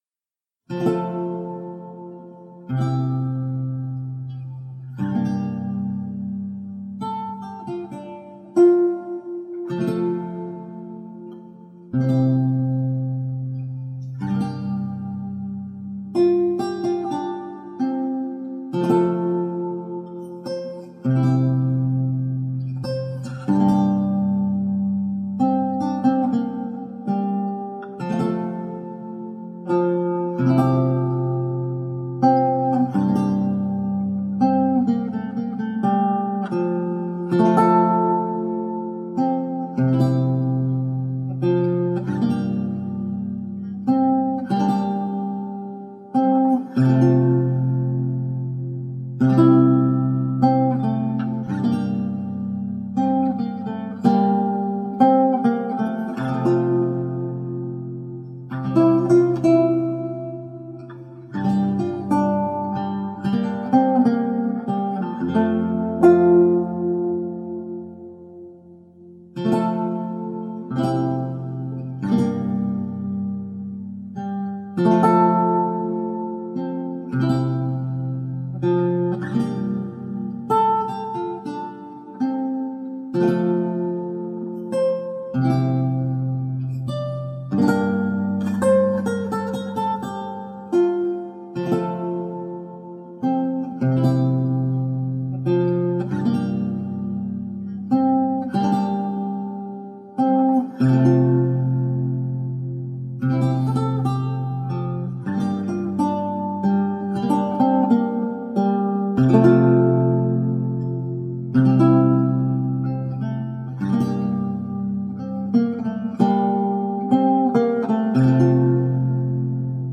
Original and evocative lute music.